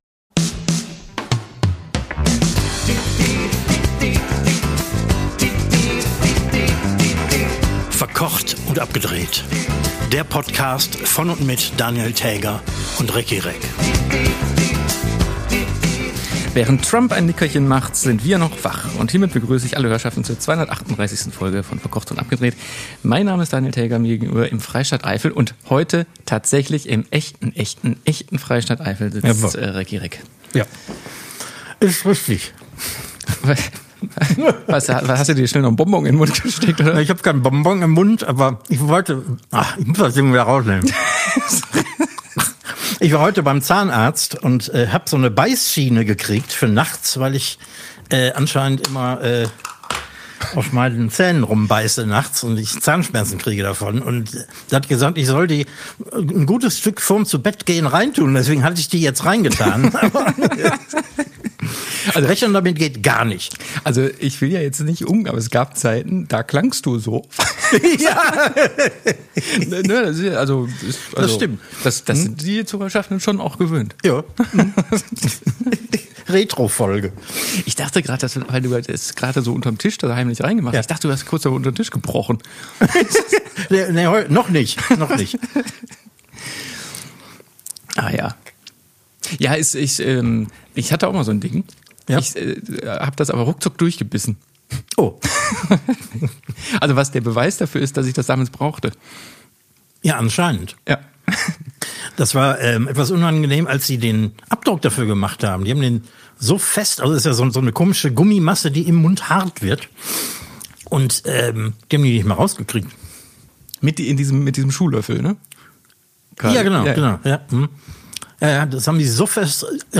Beschreibung vor 4 Monaten Alle, die sich aufgrund des Folgentitels voller Vorfreude schnell noch eine Kiste Bier aus dem Keller geholt haben, müssen wir zu unserem Leidwesen enttäuschen, denn hier geht es um wirklich Gesundes.„Verkocht und Abgedreht“ ist nicht nur an der Elfenbeinküste ein Charterfolg, sondern neuerdings auch noch Beauty- und Kosmetikpodcast. Aber der Reihe nach: Endlich herrscht, mit der 238. Folge, wieder Normalität und die Mikrofone sind mit Ihren Besitzern zurück in Köln und der Eifel - dort, wo sie hingehören.